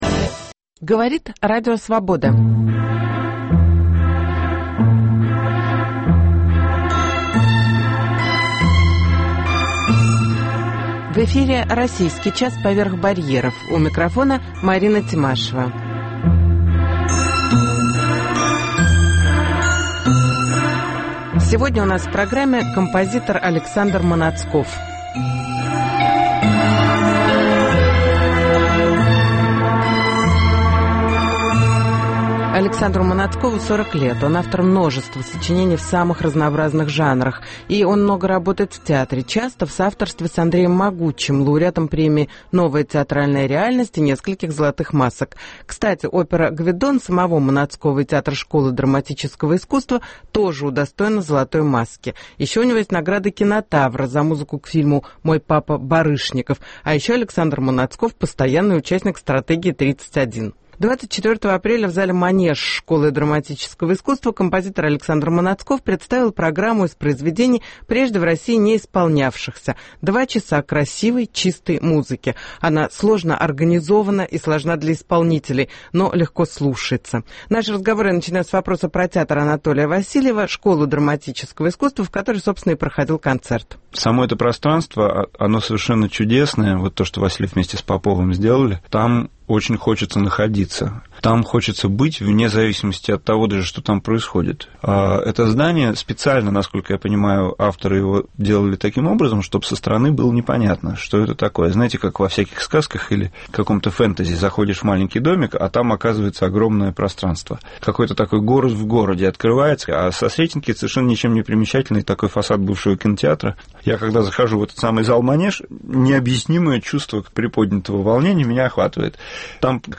Интервью с композитором